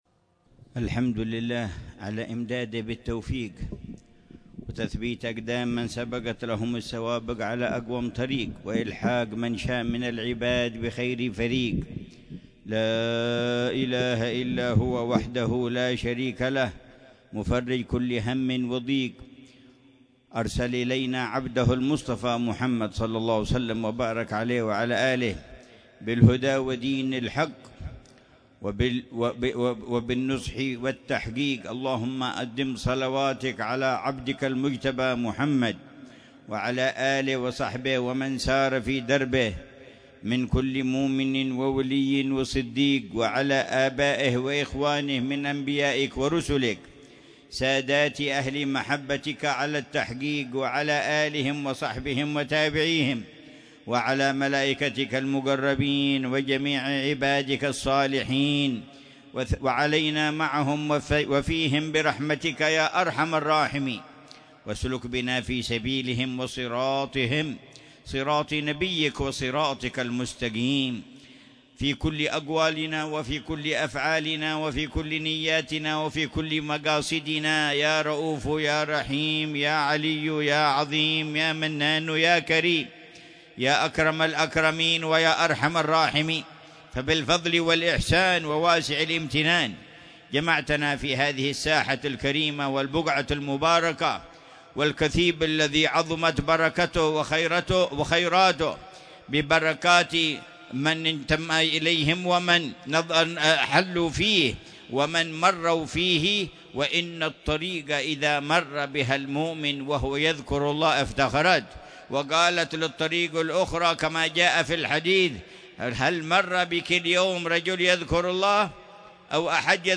مذاكرة العلامة الحبيب عمر بن محمد بن حفيظ في كثيب الشيخ أبي بكر بن سالم في منطقة عينات، ليلة الإثنين 23 ربيع الأول 1447هـ بعنوان: